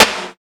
113 SNARE 2.wav